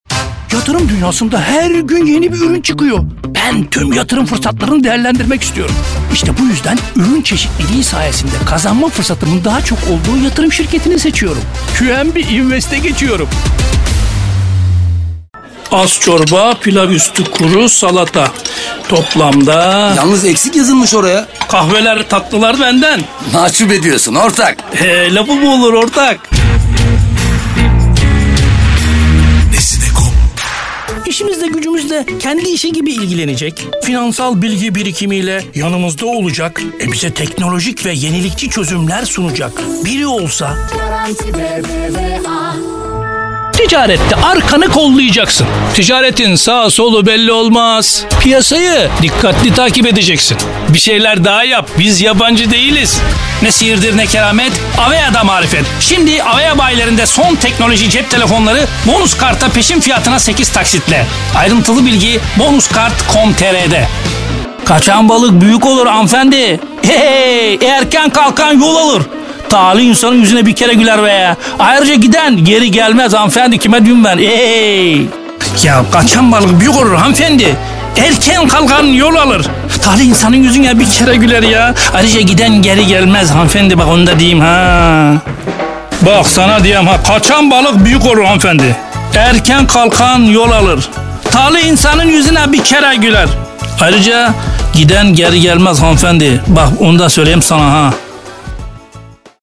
KATEGORİ Erkek
DEMO SESLERİ
Canlı, Eğlenceli, Güvenilir, Karakter, Animasyon, Şefkatli, Promosyon, Dialekt, Sıcakkanlı, Film Sesi, Parlak, Dış Ses, Olgun, Taklit, Dostane,